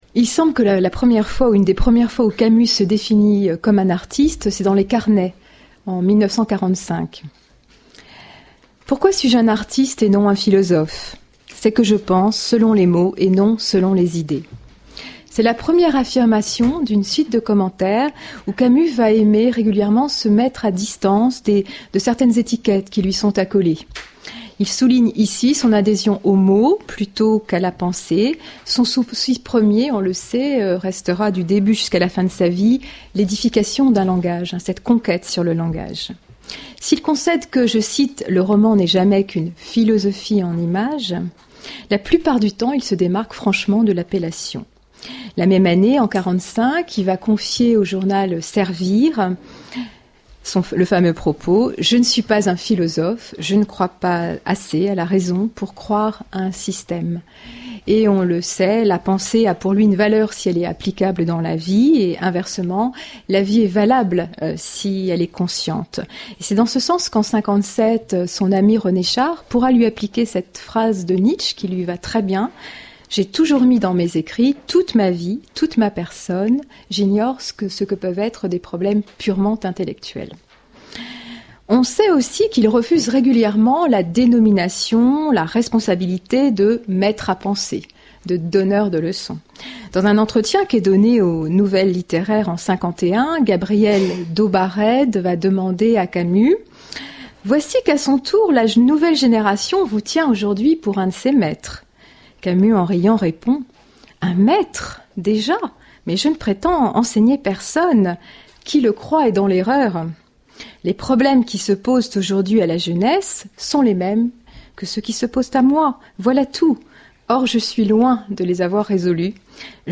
Cette introduction générale a été prononcée dans le cadre du colloque intitulé Camus l'artiste qui s'est tenu au Centre Culturel International de Cerisy, du 17 au 24 août 2013, sous la direction de